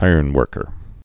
(īərn-wûrkər)